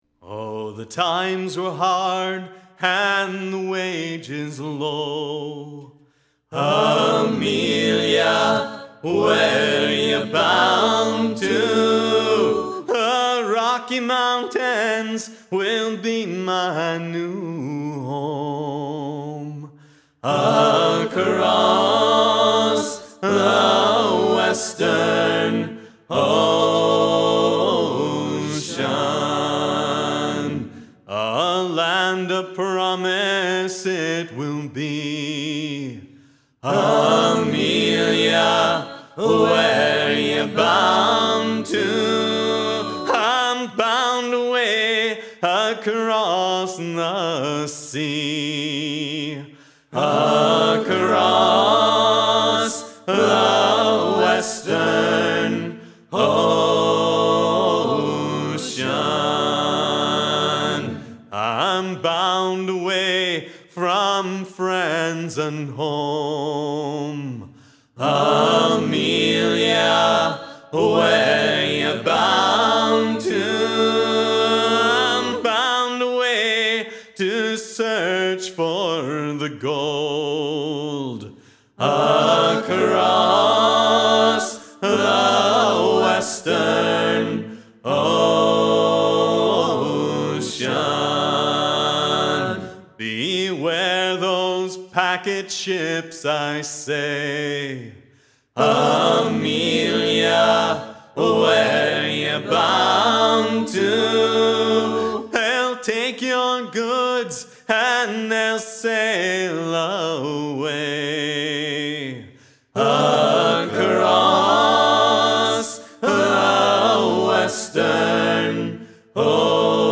The shanty Across the Western Ocean dates from the great wave of immigration to the U.S. in the 19th Century.